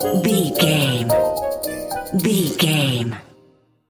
Aeolian/Minor
percussion
flute
orchestra
piano
silly
circus
goofy
comical
cheerful
perky
Light hearted
quirky